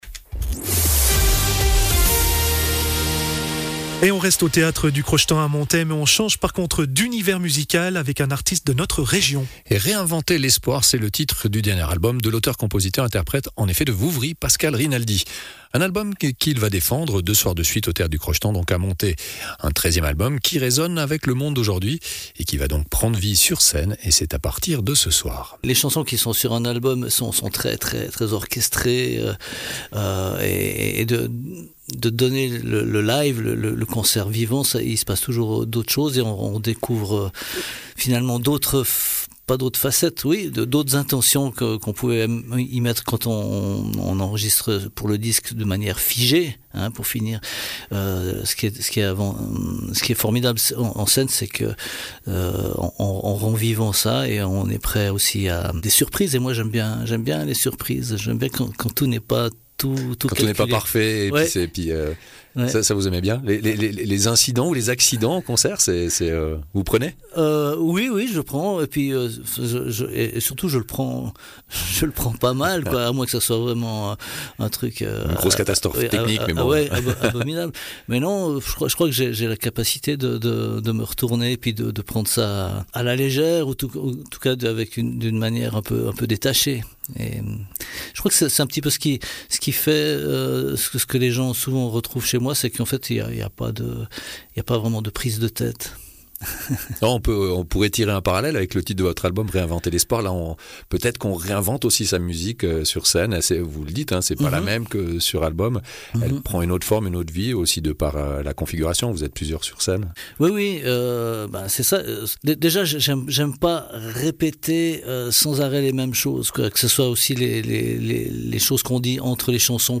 auteur-compositeur-interprète